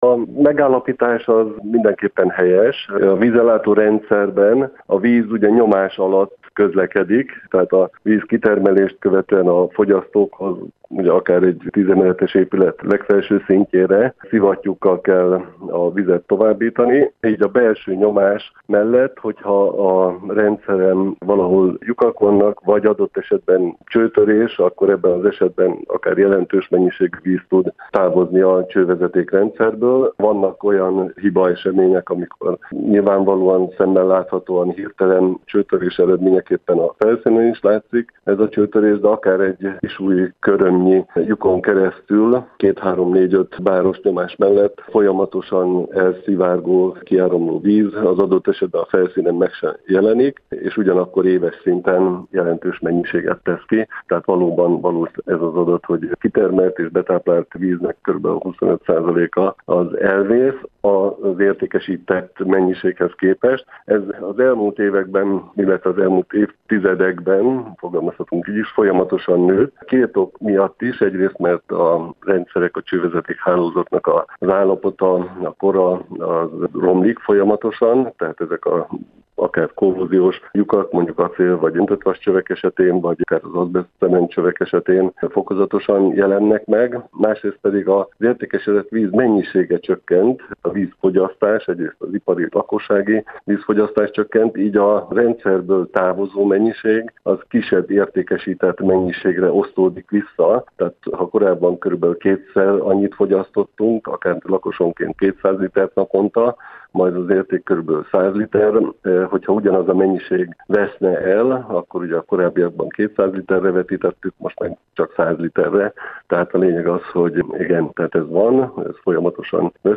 Interjú az Infó Rádióban